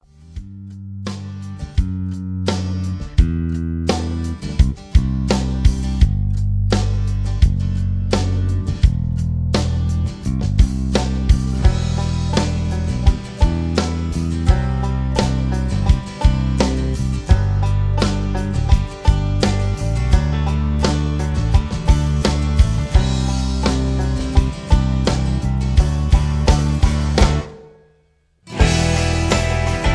(Version-3, Key-G)
mp3 backing tracks